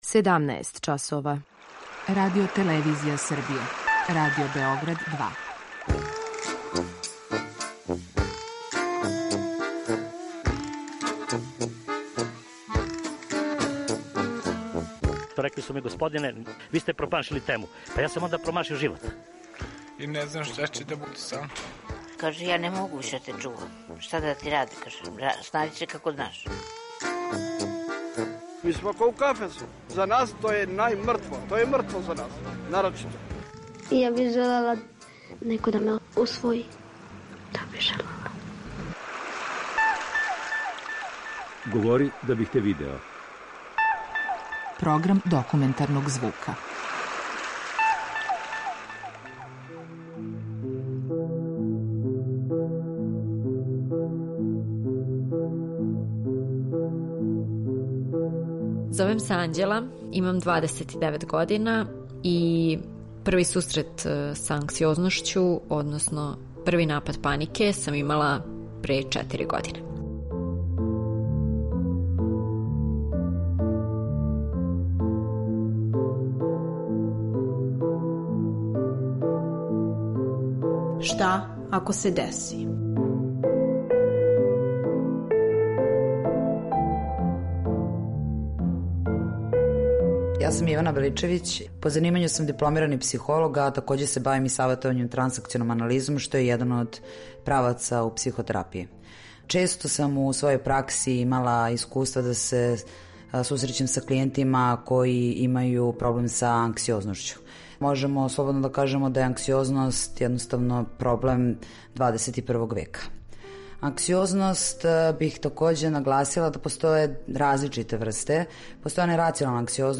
Документарни програм
Поремећаји анксиозности су данас најчешћи вид емоционалних поремећаја, са којима се носе како младе, тако и старије особе. У данашњој репортажи „Шта ако се деси", о свом искуству превазилажења анксиозног поремећаја кроз психотерапију, говориће девојка која се са тим проблемом успешно изборила.